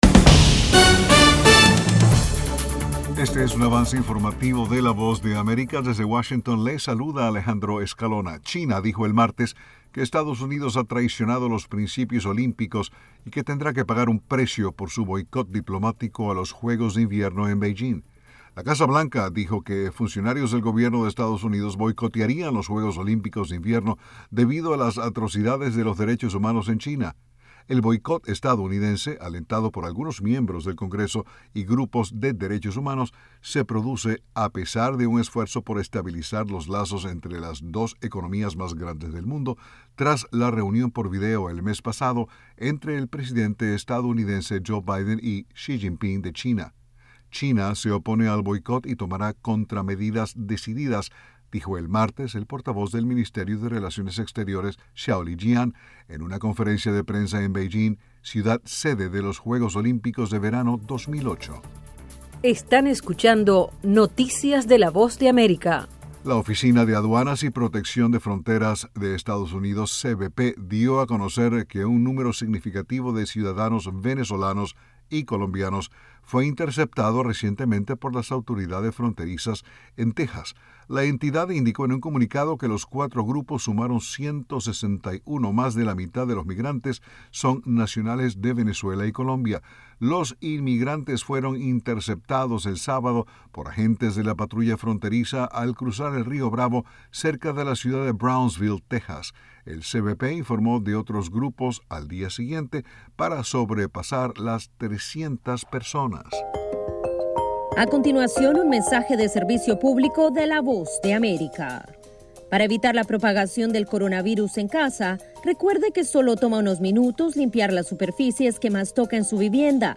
Avance Informativo 1:00pm